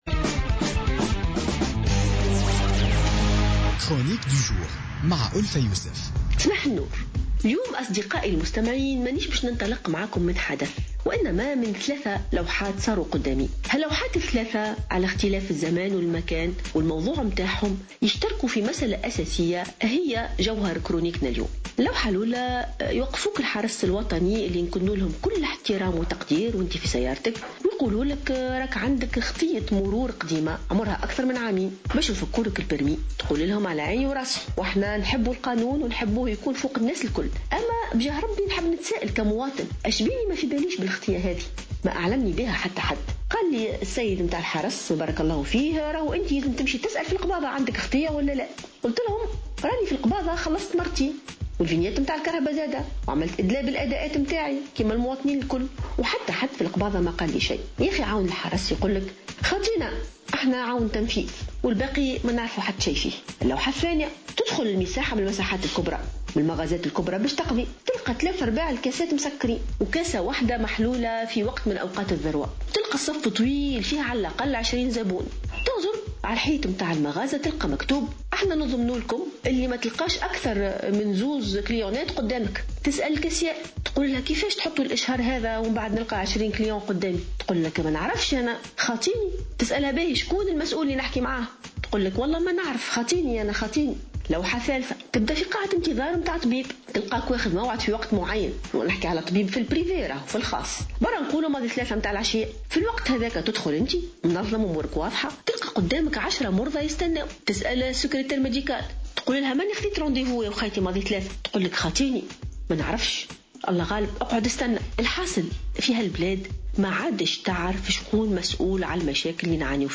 قالت الجامعية ألفة يوسف في افتتاحية اليوم الخميس إن تونس تعاني من أزمة قيادة وحوكمة جراء غياب المسؤولين في كل المستويات.